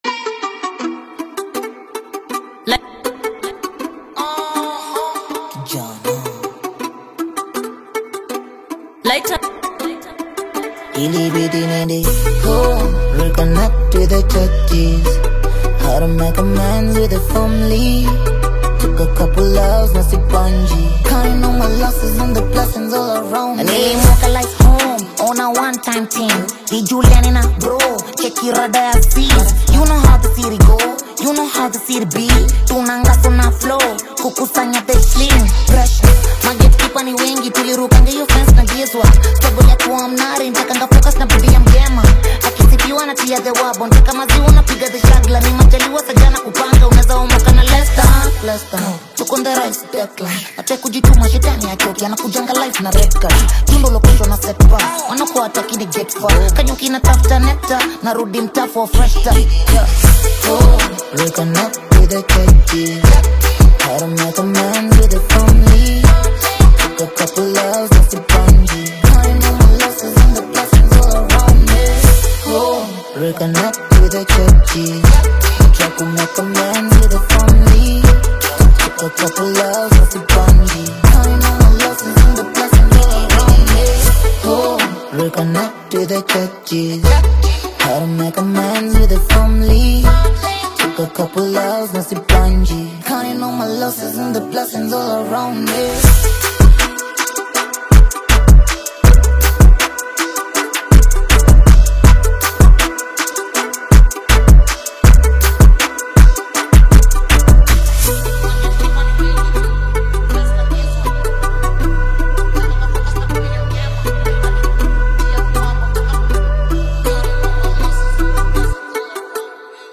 AudioHip HopKenyan Music
heartfelt Hip Hop single
warm rhythm and relatable message